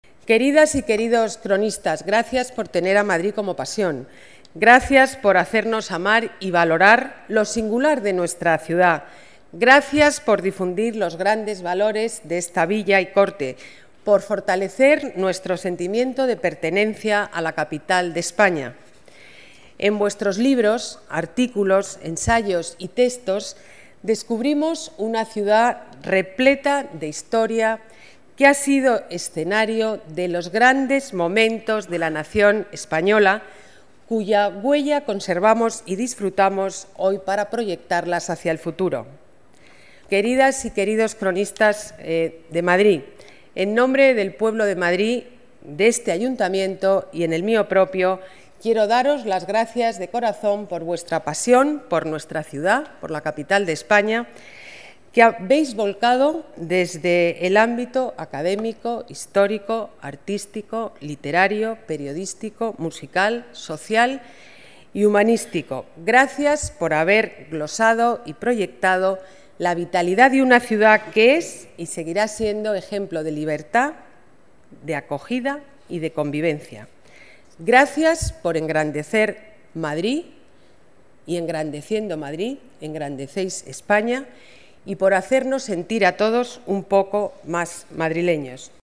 Nueva ventana:La alcaldesa en la entrega de medallas a cronistas de la Villa